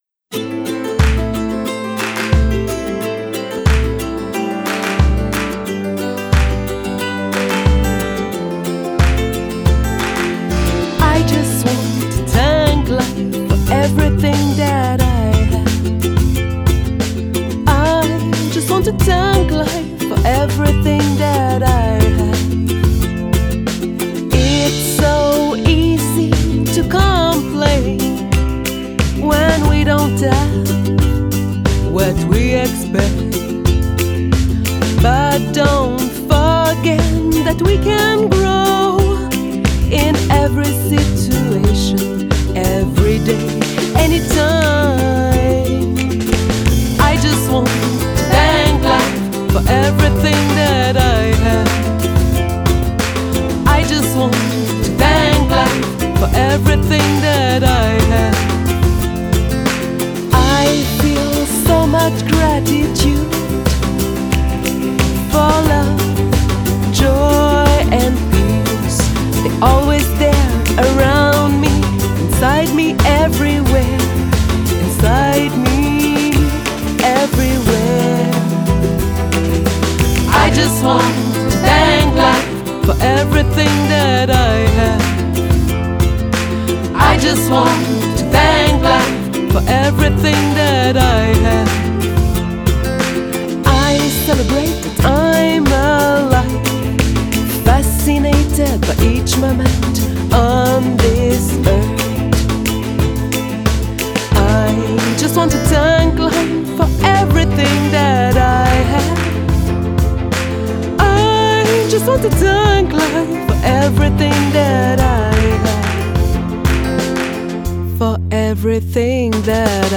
Genre: Folk, Pop